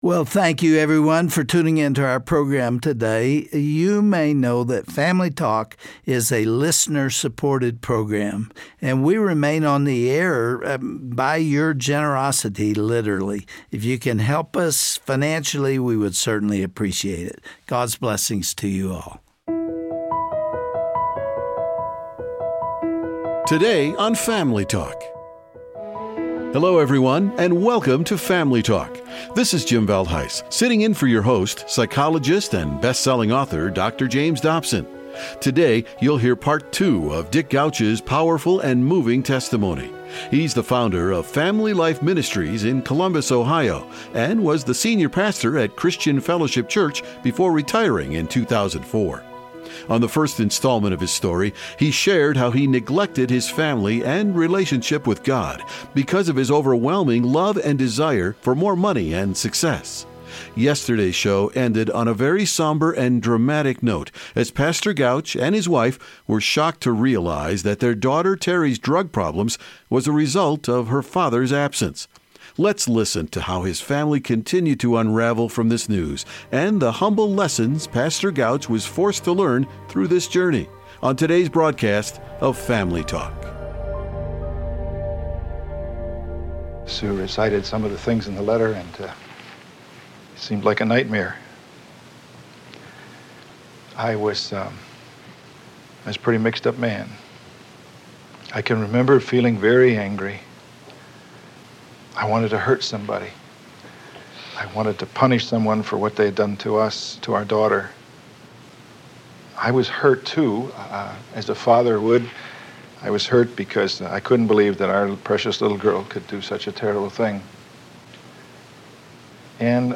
Dont miss this incredibly moving broadcast of Dr. James Dobsons Family Talk.